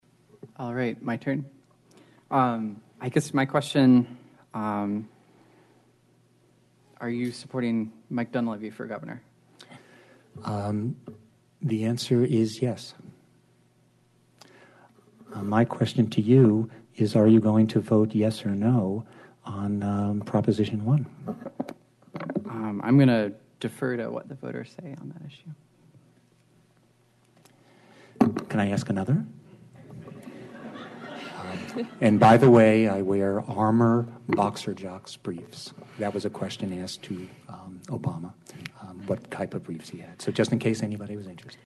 American Government students from Sitka High School on Wednesday (10-24-18) held a ninety-minute forum with candidates for House District 35, representing Sitka, Petersburg, Angoon, Hoonah, Kake, Craig, Klawock and many smaller communities Prince of Wales and Chichagof islands.
Here are the candidates’ answers to every question asked during the wide-ranging forum.
22. The candidates were then allowed to ask one question of each other.